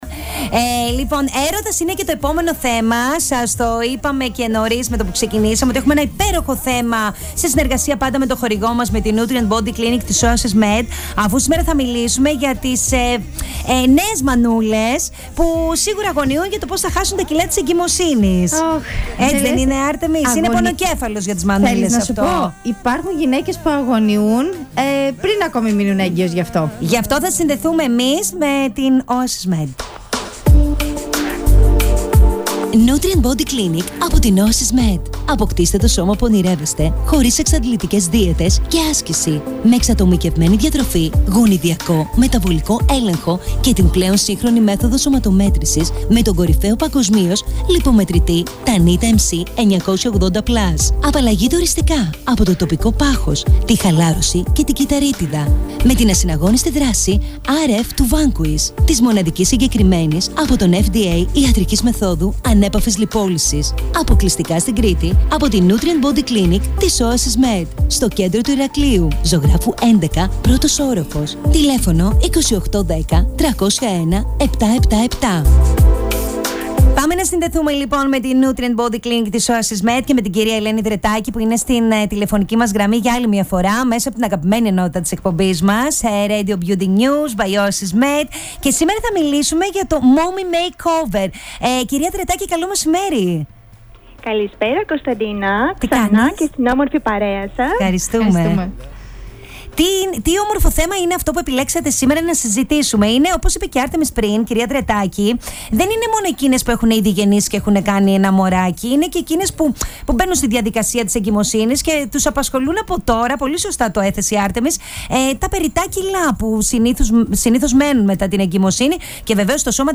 Πρόκειται για ένα Πρωτοποριακό συνδυασμό Θεραπειών αποκλειστικά για τις νέες μαμάδες μετά τον τοκετό !!!! Ακούστε όλη την συνέντευξη εδώ αλλά και τις προσφορές μέσα από το ραδιόφωνο μας !!!